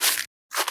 Sfx_creature_penguin_land_turn_right_01.ogg